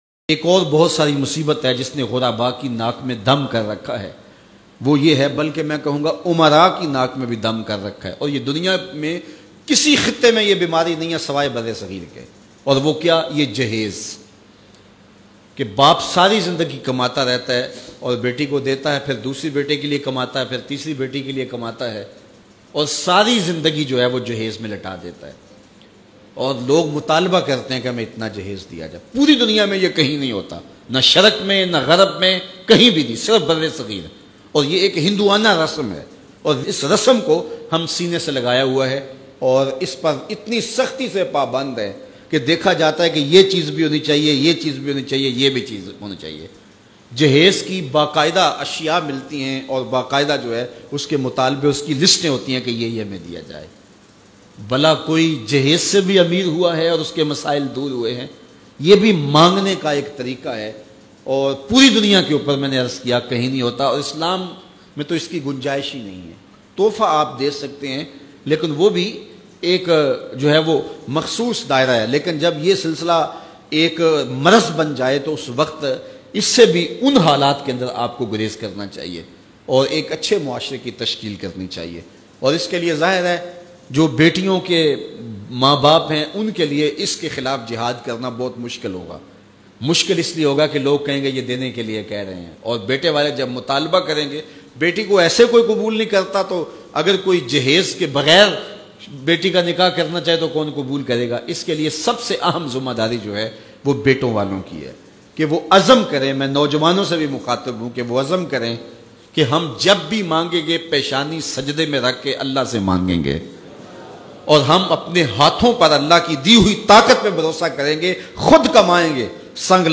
Barr e Sagheer Me Murawwaj Aesi Rasm Bayan MP3